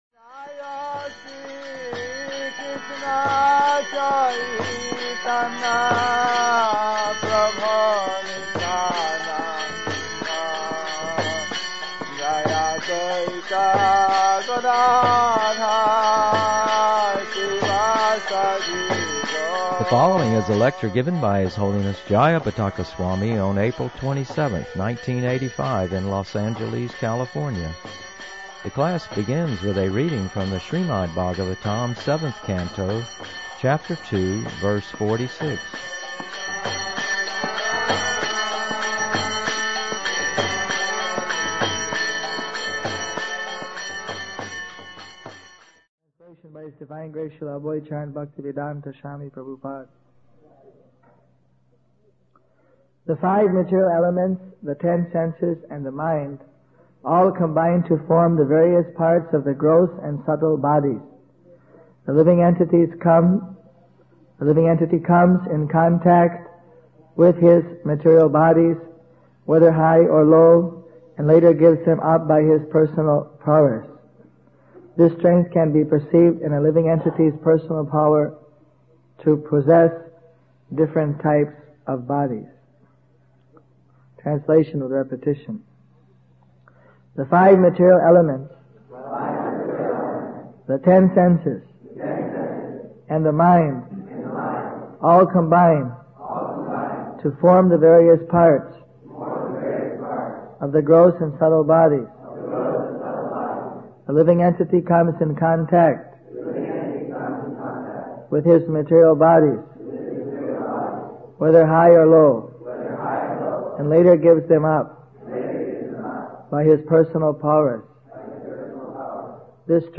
Lectures
The class begins with the reading from the Srimad Bhagavatham, seventh Canto, Chapter 2, Verse 46.